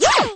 girl_toss_shock.wav